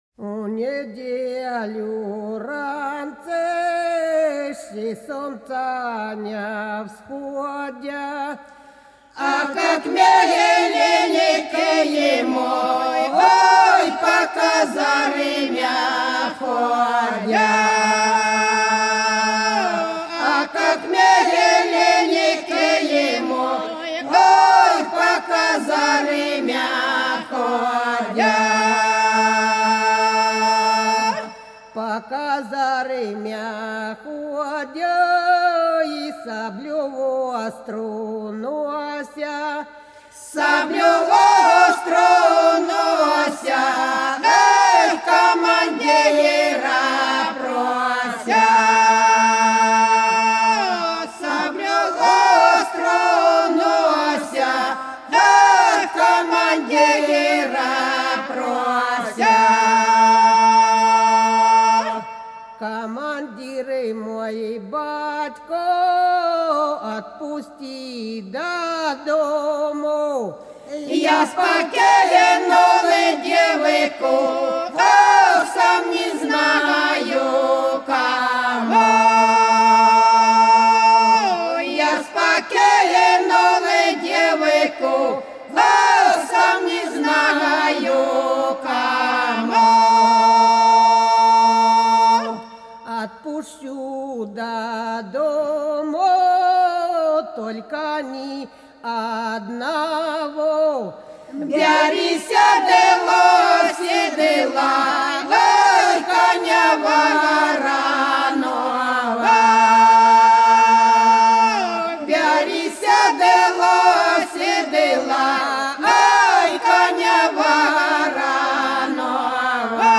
Хороша наша деревня У неделю ранцы - протяжная (с. Репенка)
11_У_неделю_ранцы_-_протяжная.mp3